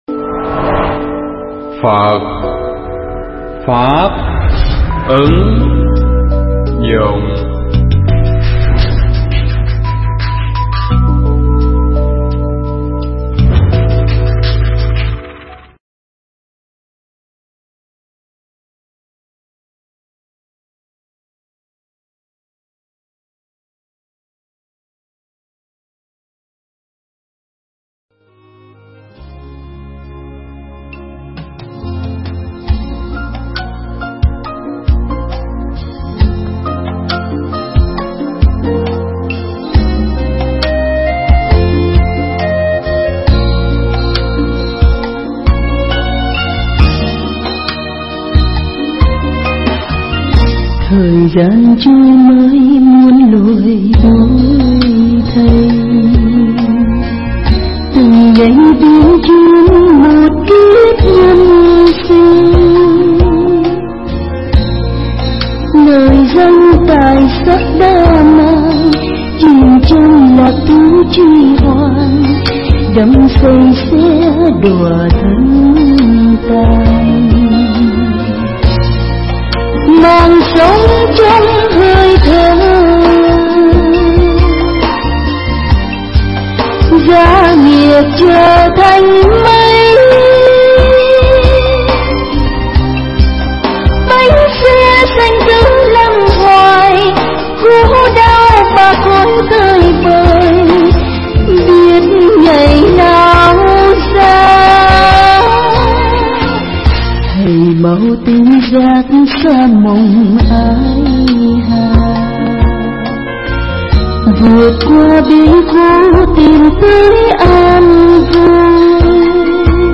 Tải mp3 Pháp thoại Ngũ Uẩn – Pháp Tu Căn Bản